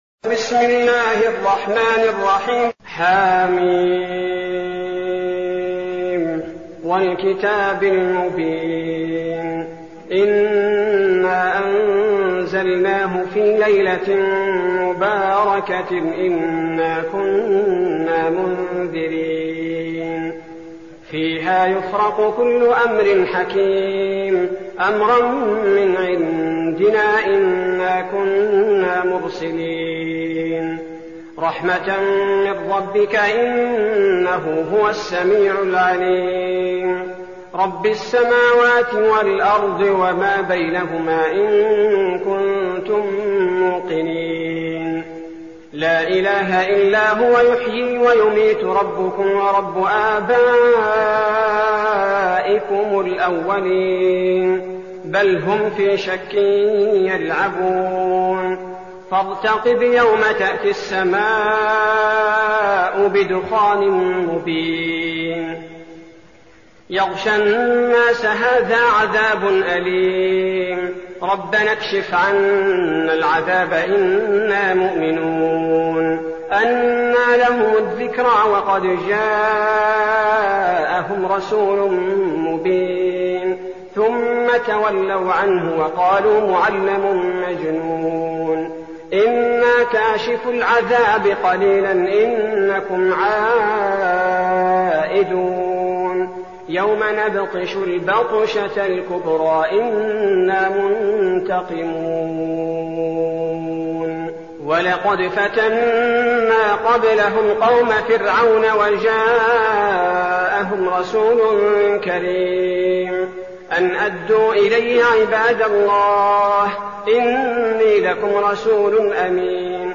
المكان: المسجد النبوي الشيخ: فضيلة الشيخ عبدالباري الثبيتي فضيلة الشيخ عبدالباري الثبيتي الدخان